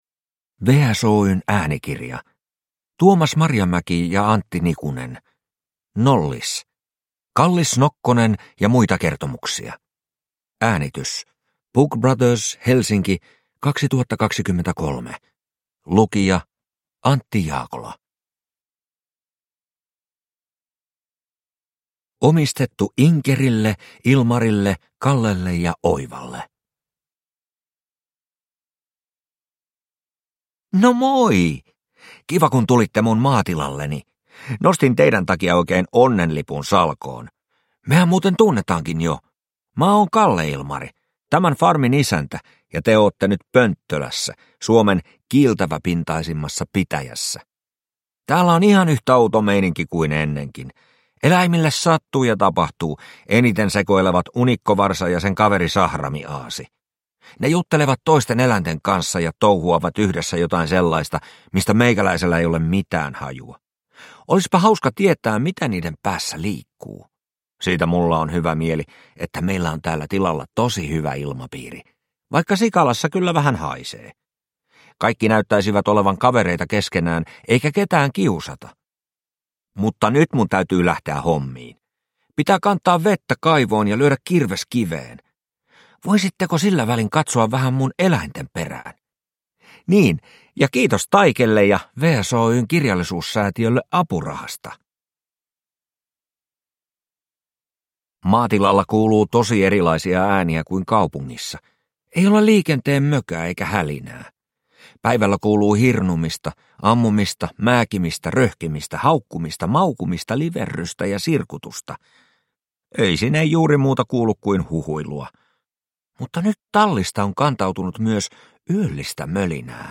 Nollis – Kallis nokkonen ja muita kertomuksia – Ljudbok